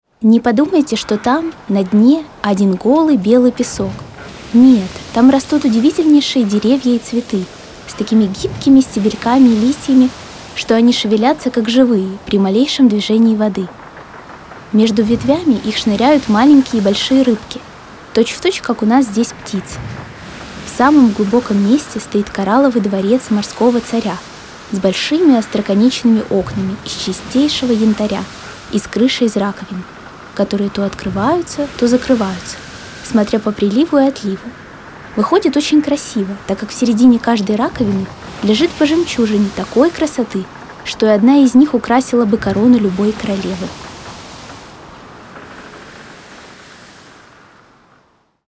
Могу озвучивать детей (как мальчиков,так и девочек), подростков, молодых и зрелых девушек.
Микрофон Samson C01U Pro, компьютер Macbook, специальное помещение для записи